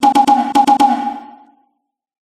ポポポンポポポン。
和風ないい音。